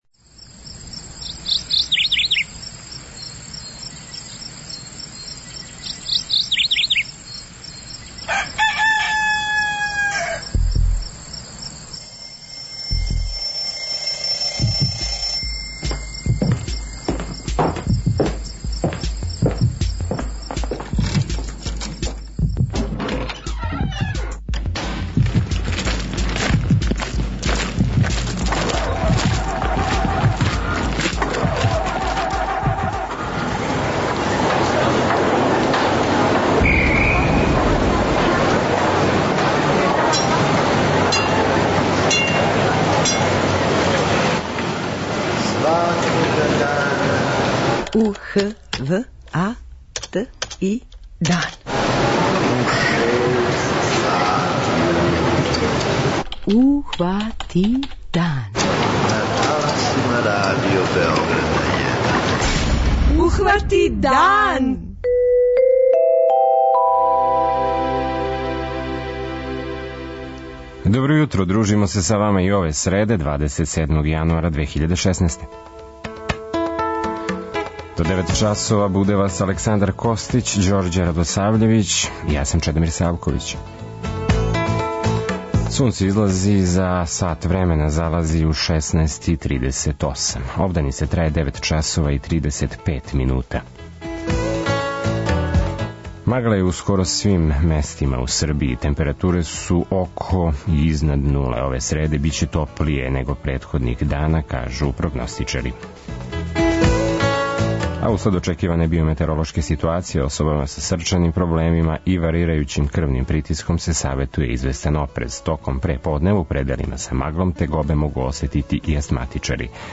За наш програм говори књижевник Милован Витезовић.
преузми : 43.15 MB Ухвати дан Autor: Група аутора Јутарњи програм Радио Београда 1!